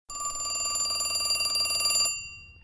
bell_ring2.wav